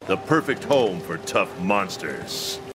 Download Tough Monsters sound effect for free.